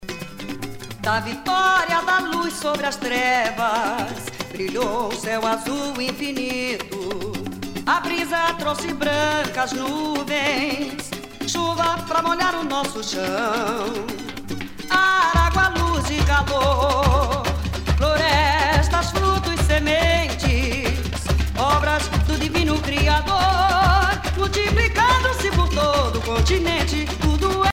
danse : samba
Pièce musicale éditée